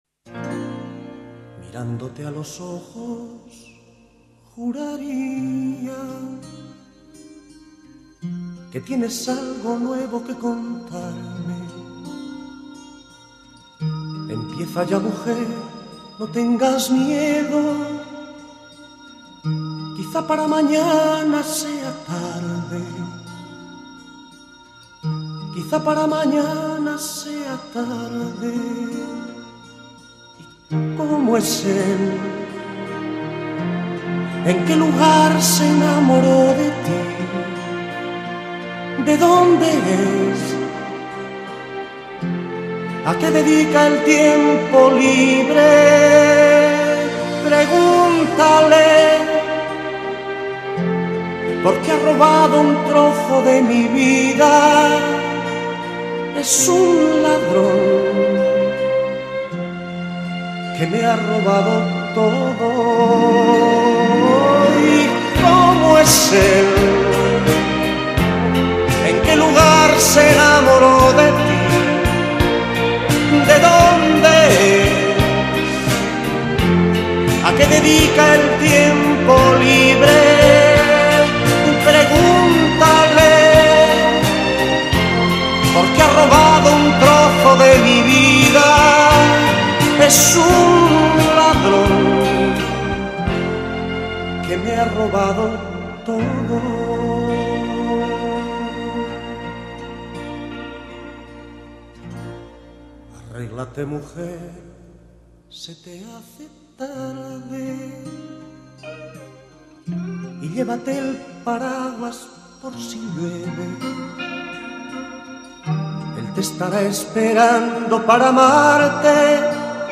Entrevistado: "José Luis Perales"